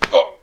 Hit1.wav